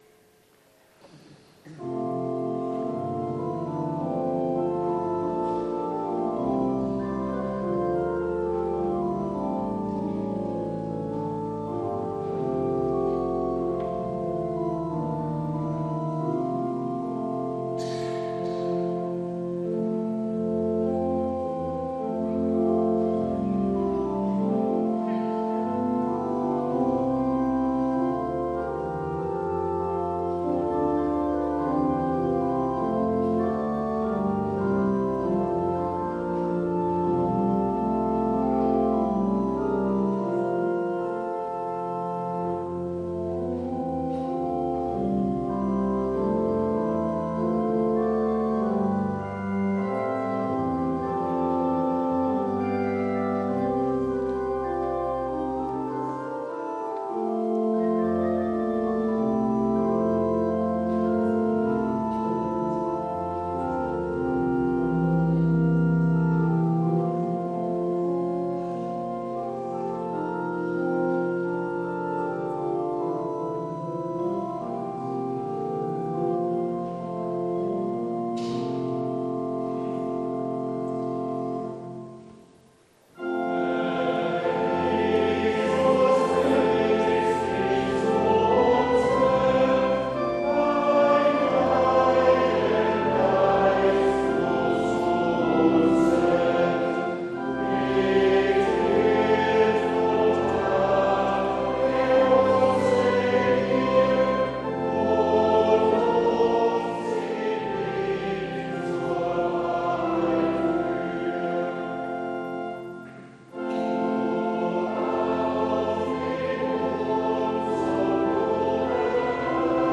Herr Jesus Christ, dich zu uns wend... (LG 193,1-3) Evangelisch-Lutherische St. Johannesgemeinde
Audiomitschnitt unseres Gottesdienstes vom 3. Sonntag nach Trinitatis 2022.